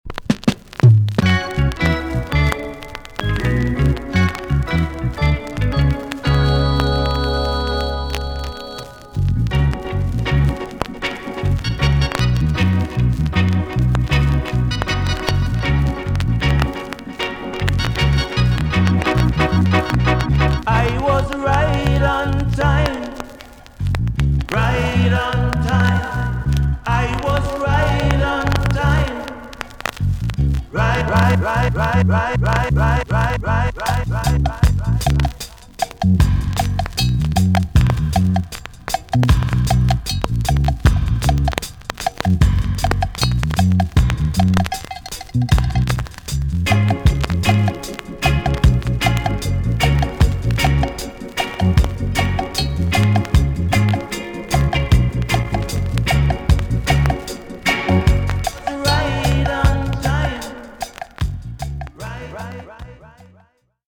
TOP >REGGAE & ROOTS
B.SIDE Version
VG+ 軽いチリノイズが入ります。